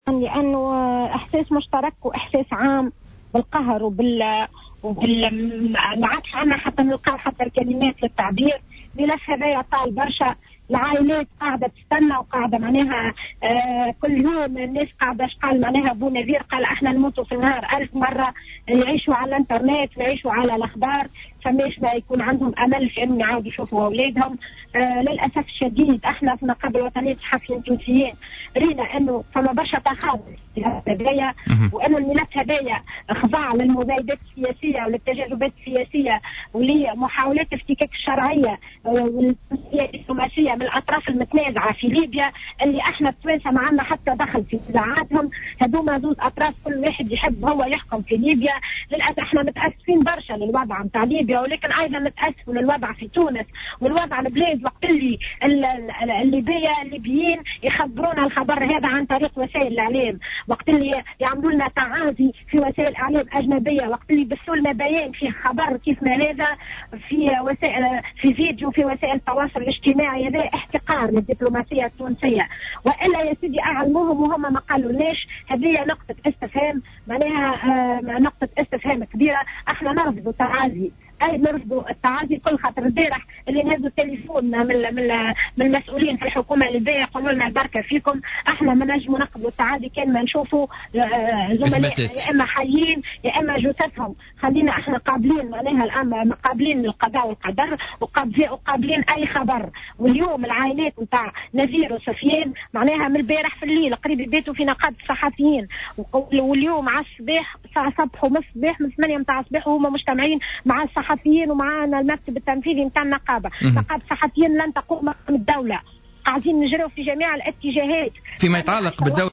في تصريح للجوهرة أف أم اليوم الخميس خلال حصة بوليتيكا